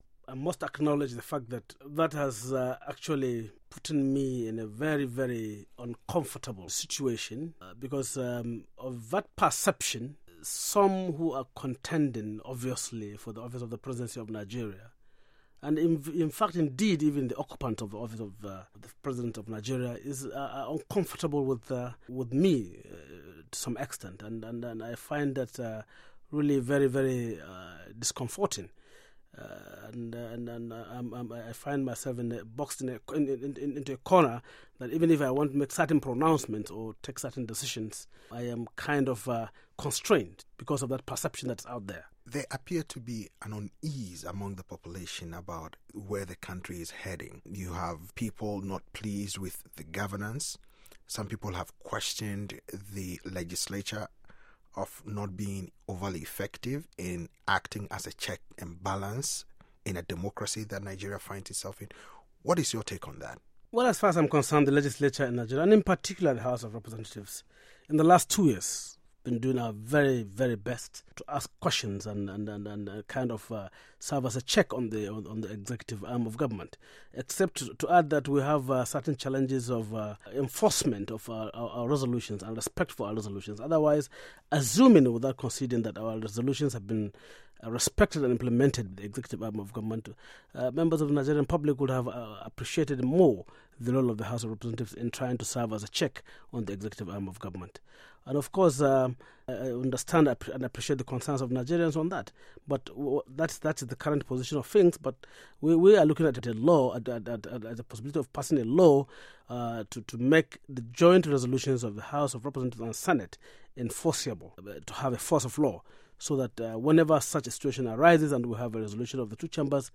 interview with Hon. Aminu Tambuwal, Nigeria House Speaker